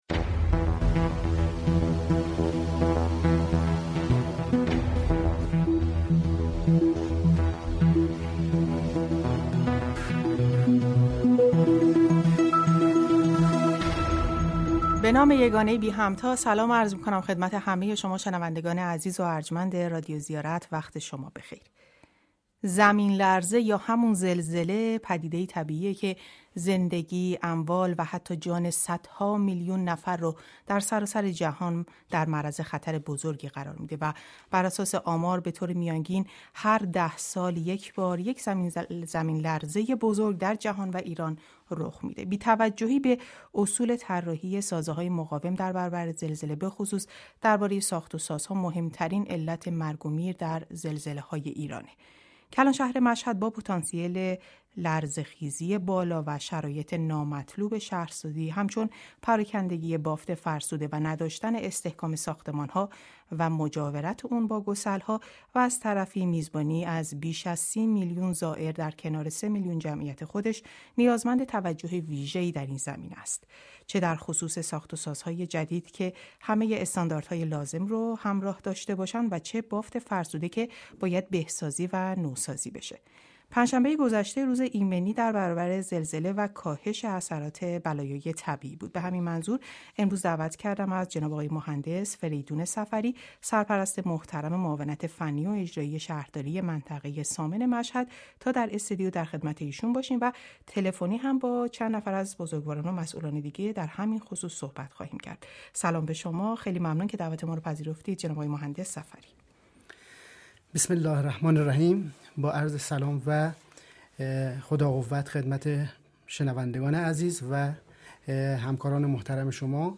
در گفتگوی ویژه خبری رادیو زیارت به این موضوع و اقدامات صورت گرفته در شهرداری منطقه ثامن و اداره پدافند غیر عامل و پیشگیری از بحران شهرداری مشهد پرداخته شد.